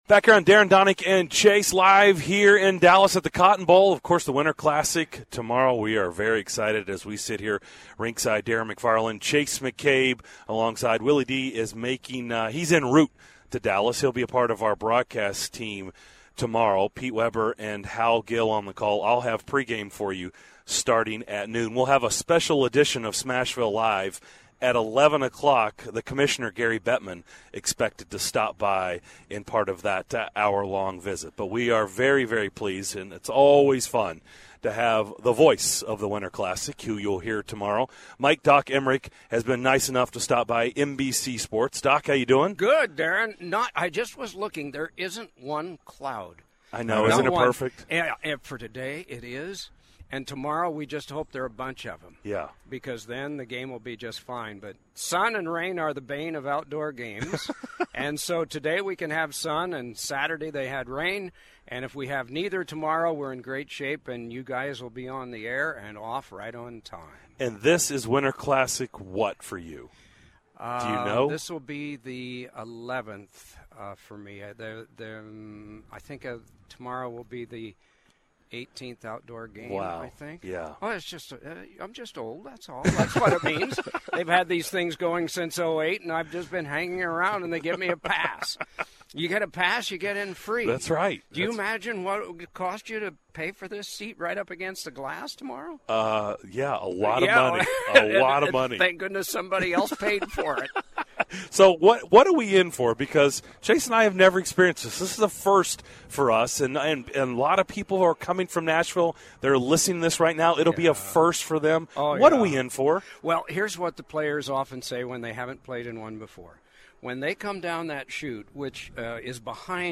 live from the Winter Classic in Dallas! Legendary play-by-play announcer Mike "Doc" Emrick joins the guys rinkside to preview the game, discuss his Winter Classic memories, and tell some tall tales about the Cotton Bowl.